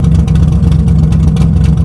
charger_idle.wav